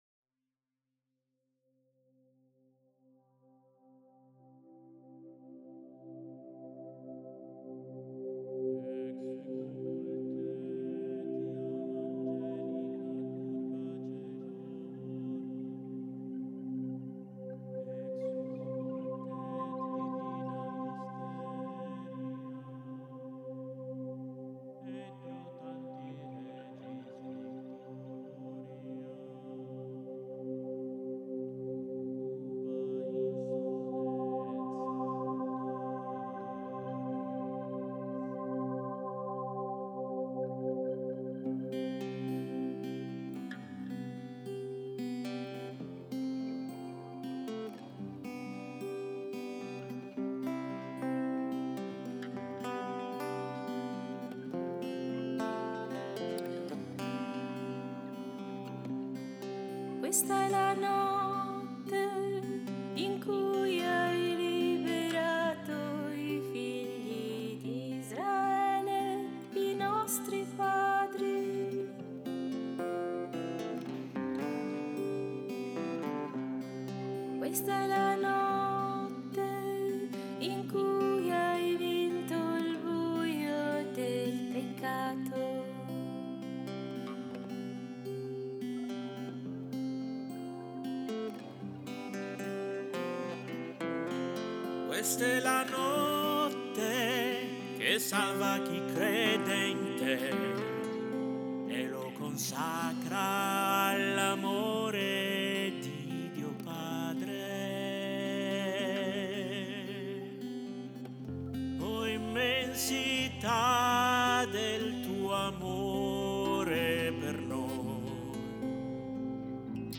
Coro
Esattamente per questi motivi, abbiamo registrato voci e musiche di alcuni di questi canti, trascrivendone gli spartiti, perché restino a disposizione di chi vorrà lodare e ringraziare il Signore e la Sua dolcissima Madre.